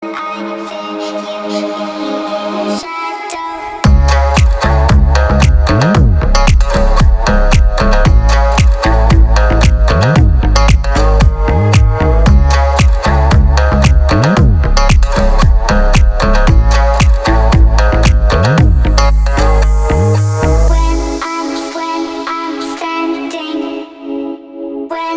• Качество: 320, Stereo
восточные мотивы
dance
забавный голос
house